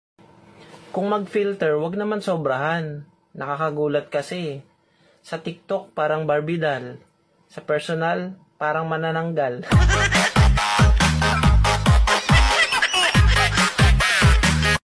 Haha Sound Effects Free Download